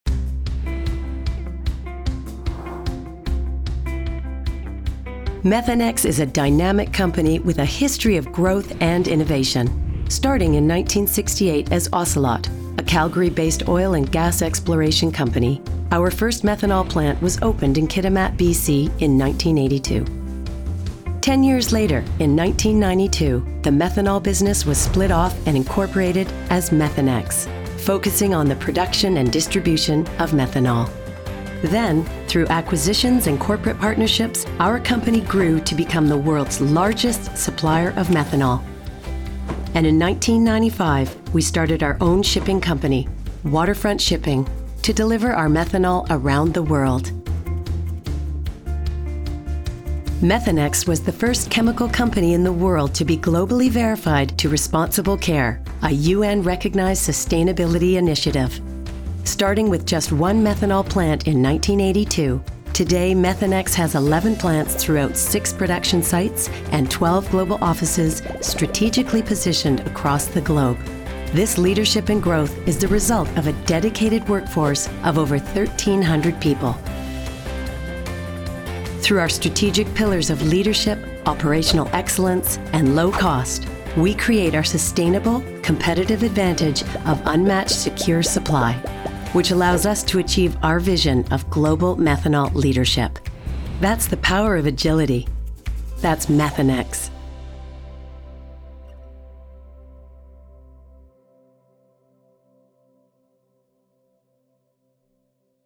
Services available in Canadian and American
Commercial Voice Over Corporate Voice Over Narration Voice Over Animation Voice Over